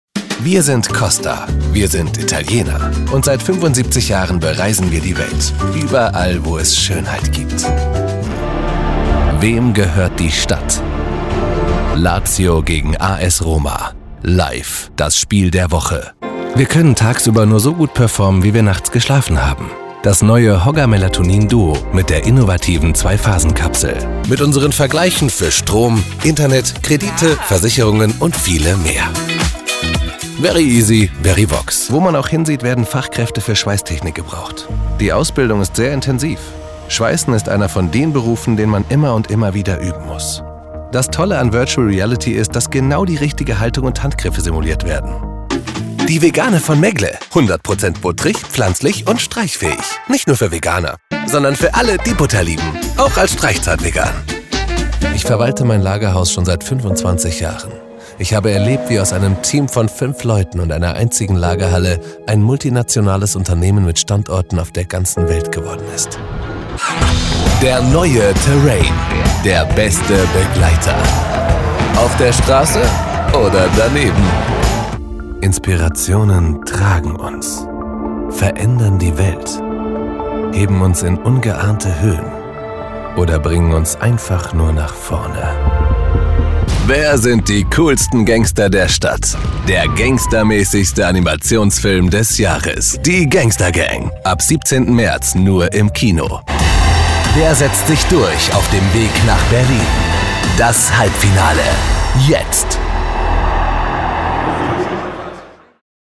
Hire Podcasts Voice Over Artists
Female
Assured , Authoritative , Bright , Bubbly , Character , Cheeky , Children , Confident , Cool , Corporate , Deep , Engaging , Friendly , Gravitas , Natural , Posh , Reassuring , Sarcastic , Smooth , Soft , Streetwise , Wacky , Warm , Witty , Versatile , Young